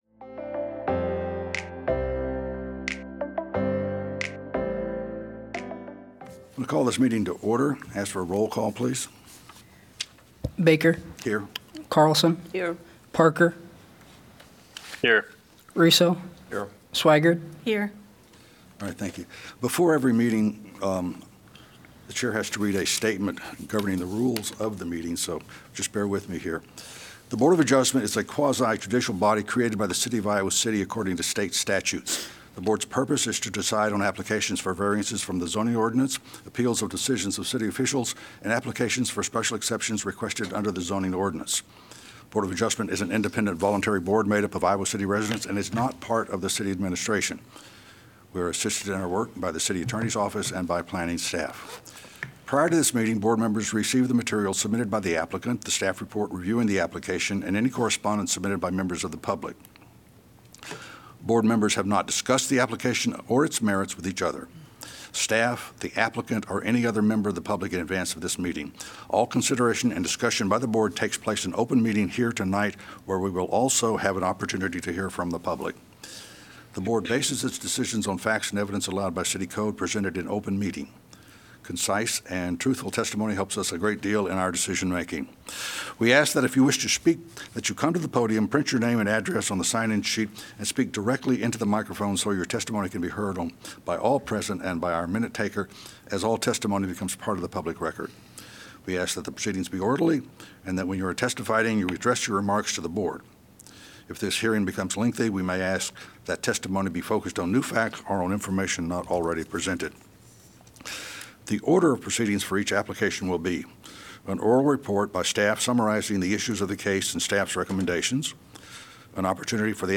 Regular monthly meeting of the City of Iowa City's Board of Adjustment.